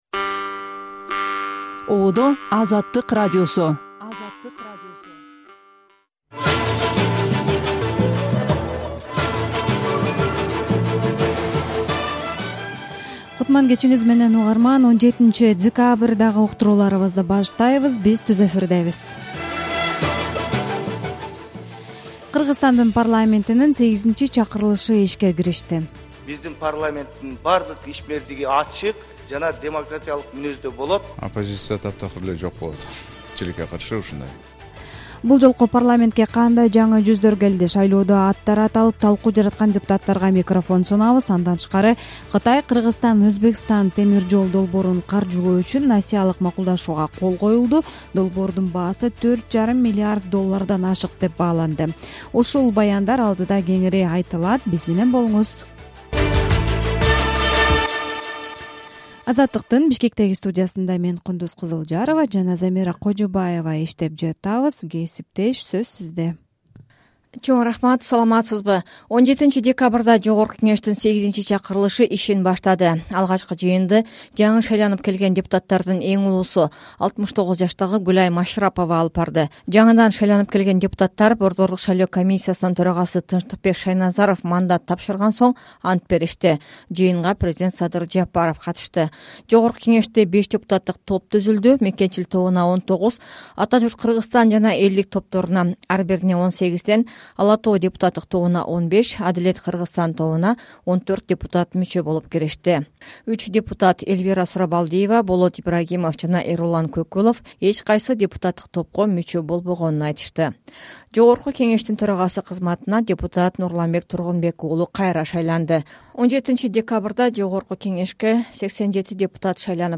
Жаңылыктар